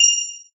count_gems_01.ogg